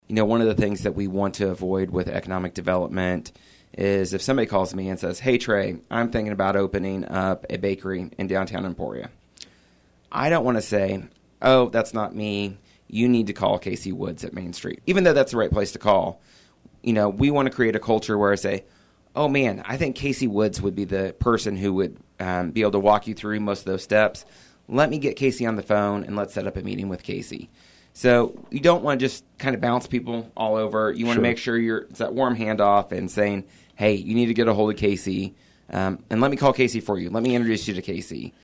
Q&A With Trey is a recurring segment with Emporia City Manager Trey Cocking on KVOE's Talk of Emporia every third Monday.